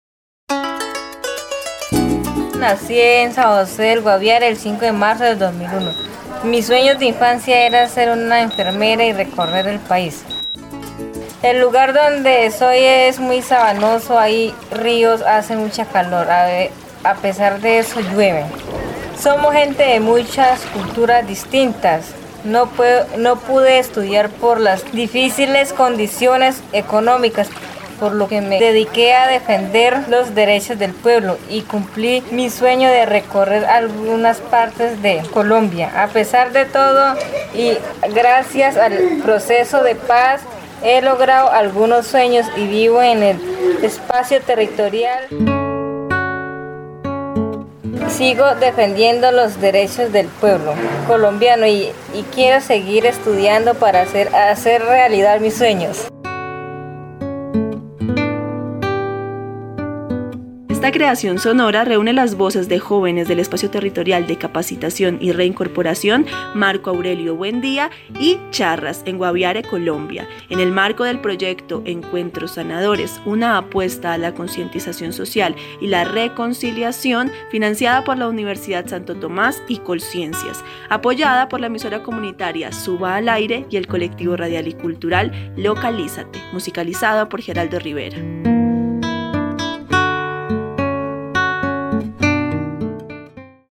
Una joven excombatiente narra aspectos de su vida y habla de sus anhelos de paz.
A young ex-combatant narrates aspects of her life and talks about her longings for peace.